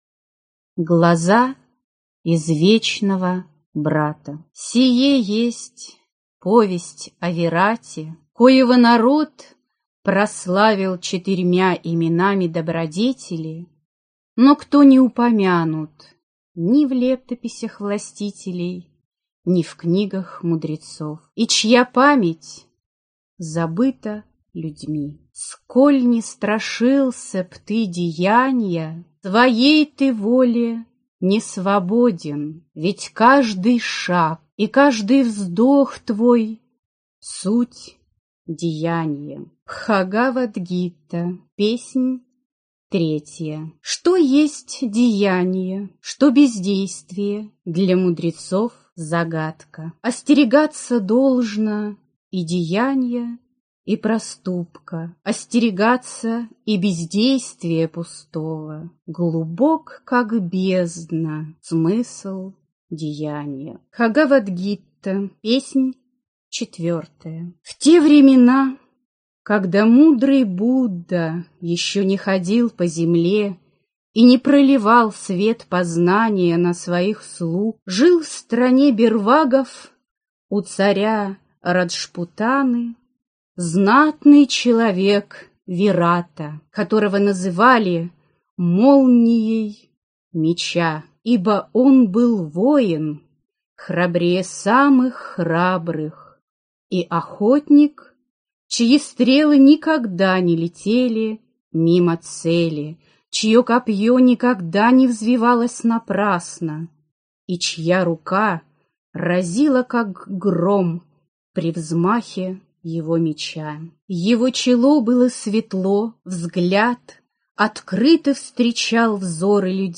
Аудиокнига Глаза извечного брата | Библиотека аудиокниг
Прослушать и бесплатно скачать фрагмент аудиокниги